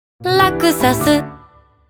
サウンドロゴ